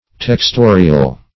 Search Result for " textorial" : The Collaborative International Dictionary of English v.0.48: Textorial \Tex*to"ri*al\, a. [L. textorius, fr. textor a weaver, fr. texere, textum, to weave.] Of or pertaining to weaving.